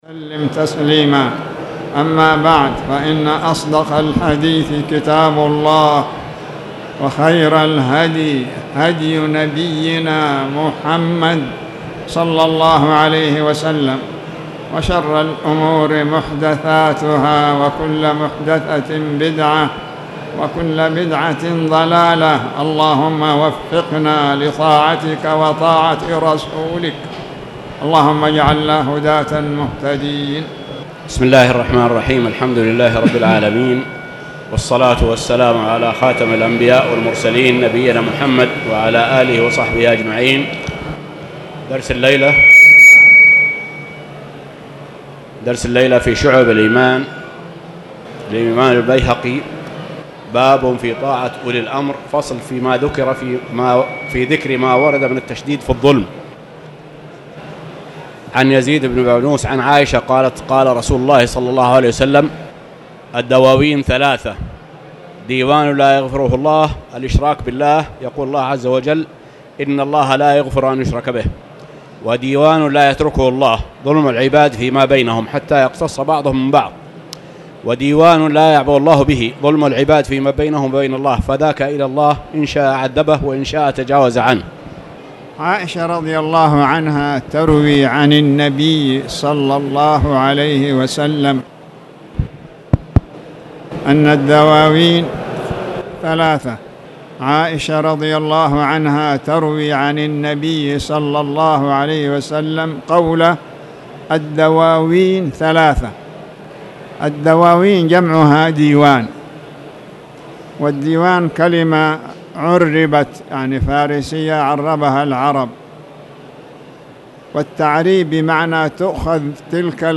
تاريخ النشر ١٥ صفر ١٤٣٨ هـ المكان: المسجد الحرام الشيخ